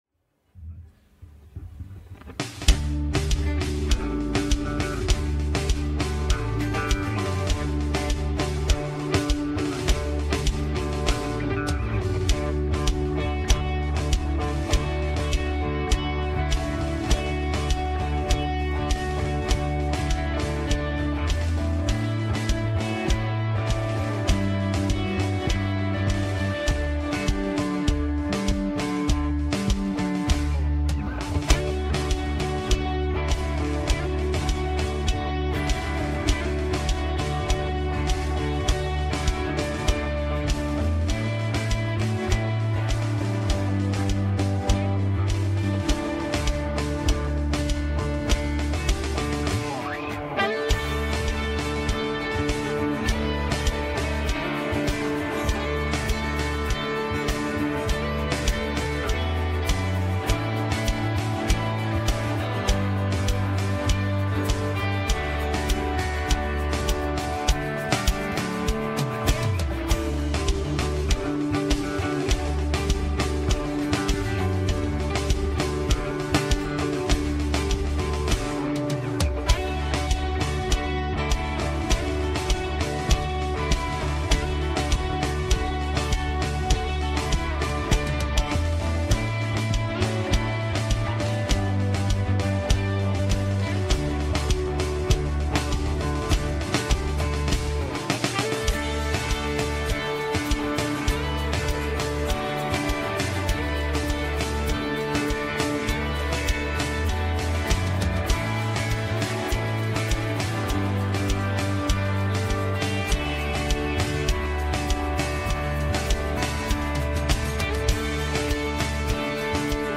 Westgate Chapel Sermons C&MA DNA: Christ Our Healer Jun 01 2025 | 01:30:17 1x 00:00 / 01:30:17 Subscribe Share Apple Podcasts Overcast RSS Feed Share Link Embed